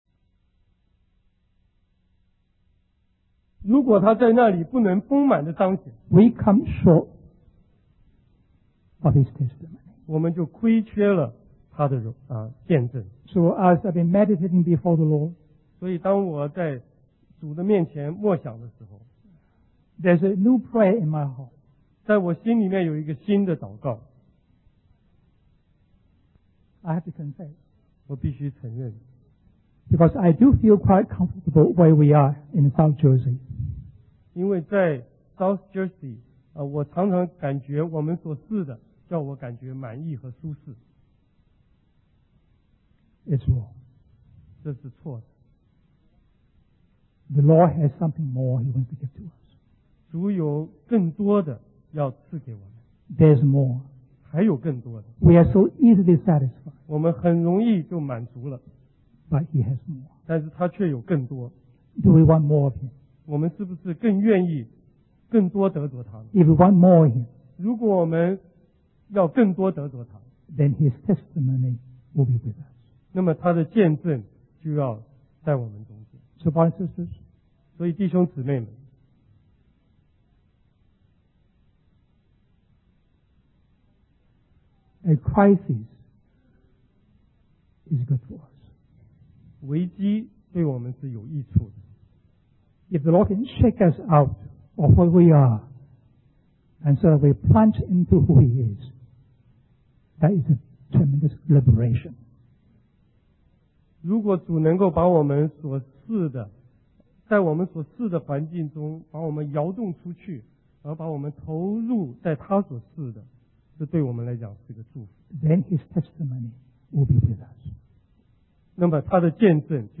In this sermon, the preacher discusses the story of Jesus turning water into wine at a wedding feast. He suggests that this crisis was created by God to manifest His glory and for the disciples to truly believe in Him. The preacher emphasizes that God wants us to seek Him and experience His fullness.